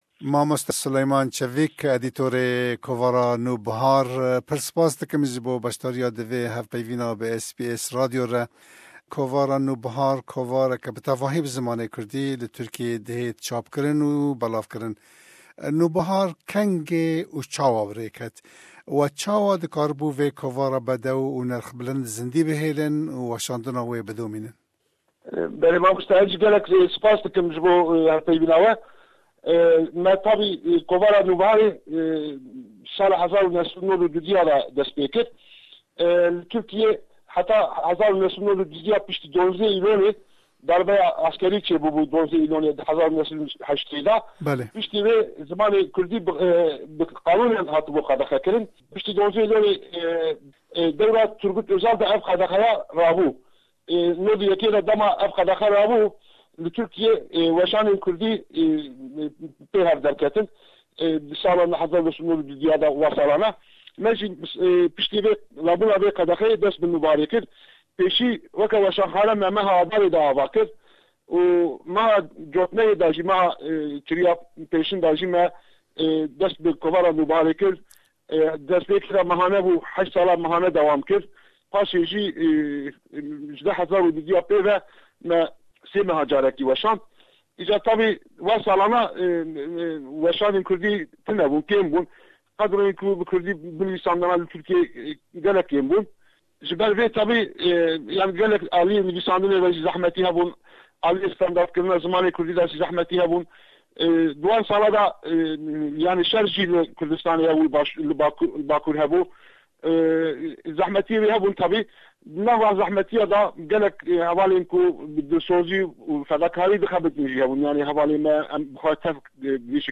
NÛBIHAR: 25 sal - Hevpeyvîn